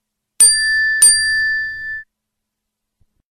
Nada Notifikasi Suara Lonceng Teng Teng
Genre: Nada notifikasi